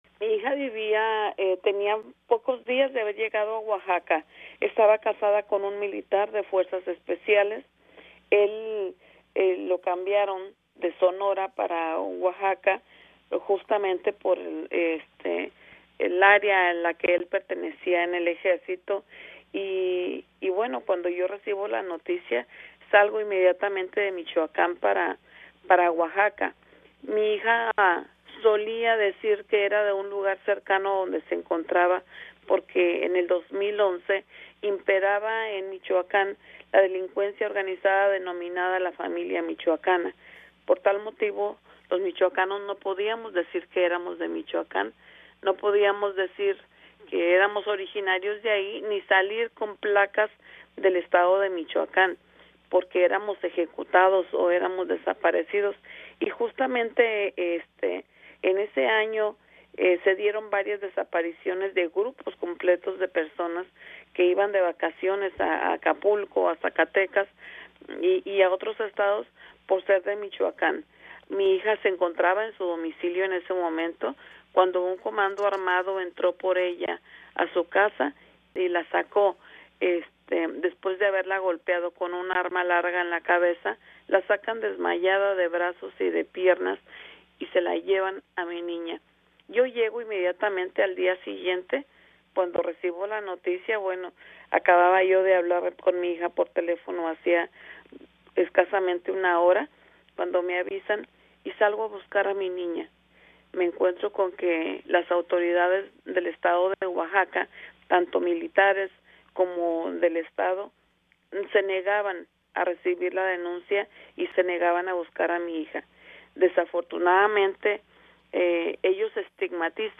Ella cuenta en entrevista con Radio Canadá Internacional la crónica de su desaparición.